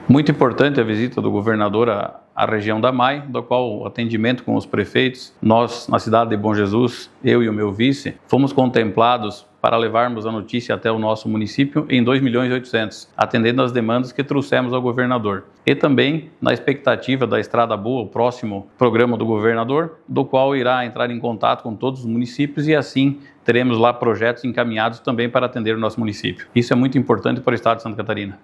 Após a conversa individual com o governador Jorginho Mello, o prefeito de Bom Jesus, Vilmar Peccini, destacou a importância desse investimento para a cidade: